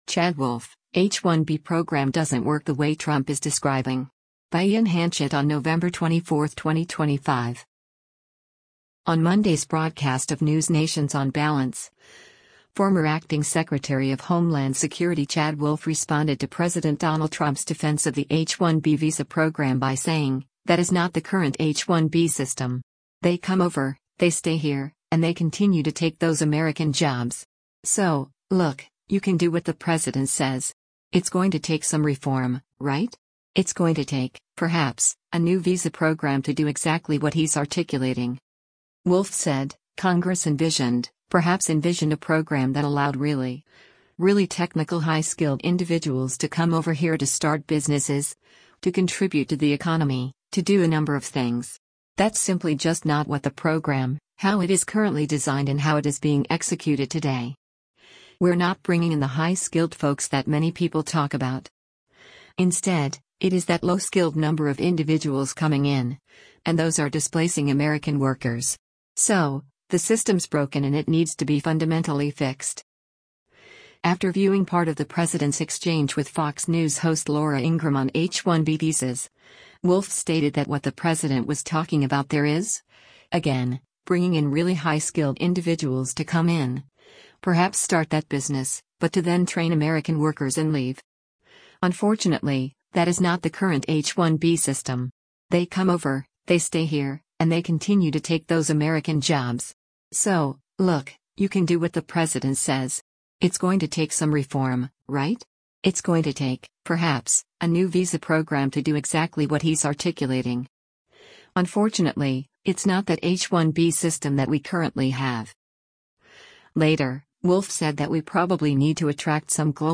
On Monday’s broadcast of NewsNation’s “On Balance,” former acting Secretary of Homeland Security Chad Wolf responded to President Donald Trump’s defense of the H-1B visa program by saying, “that is not the current H-1B system.